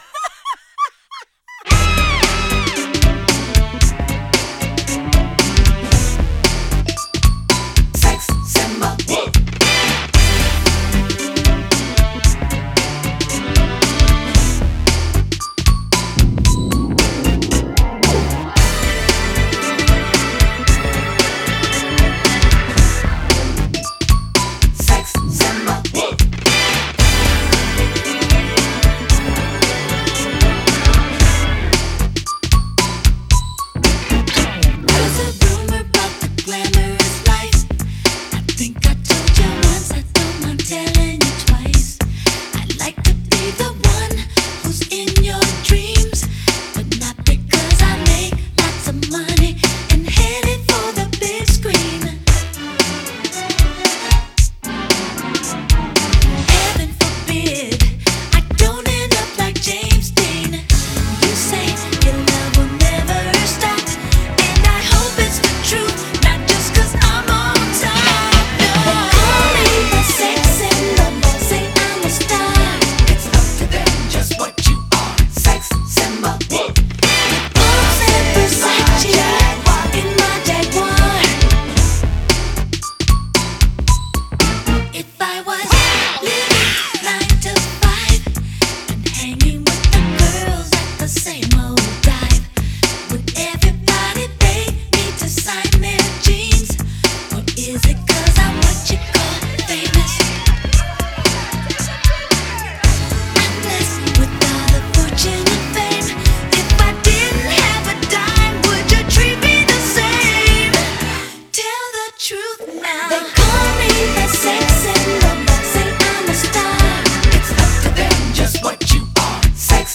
Recorded at: Brownmark Studio